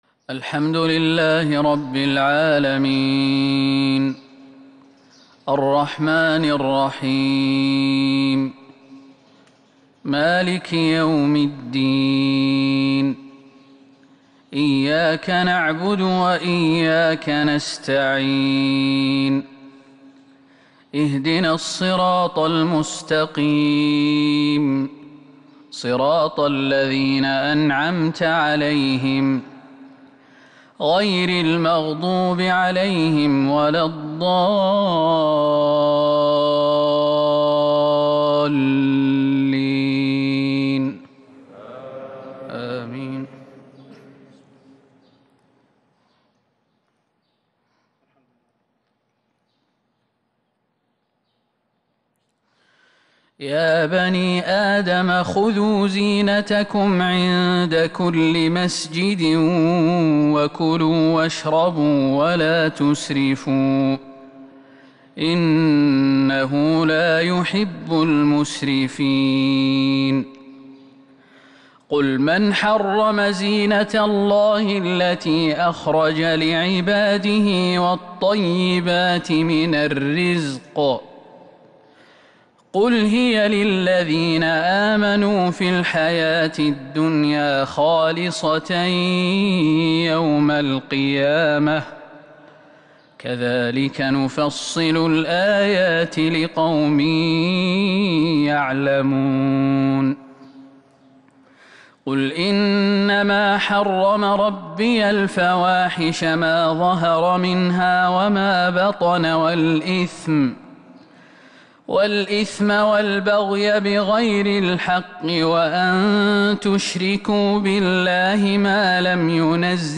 فجر الخميس 8-6-1442 هــ من سورة الأعراف | Fajr prayer from Surat Al-A'raaf 21/1/2021 > 1442 🕌 > الفروض - تلاوات الحرمين